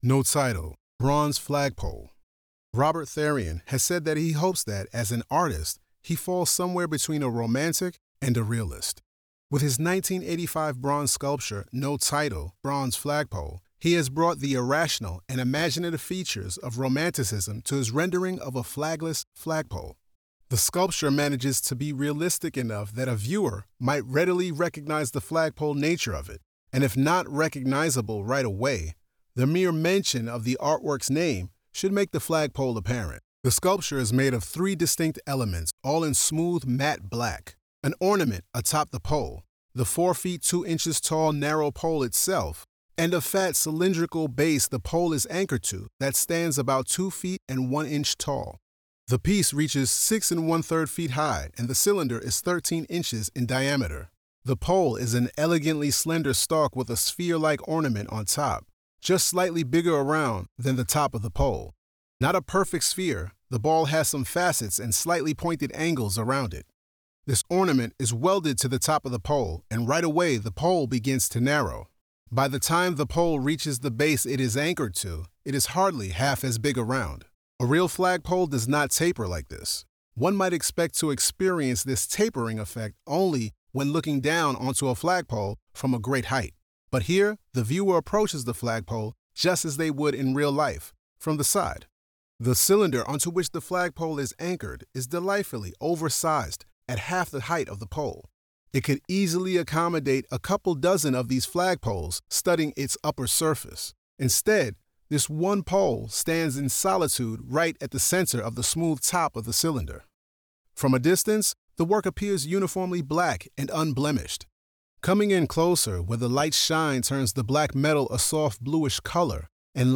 Audio Description (02:45)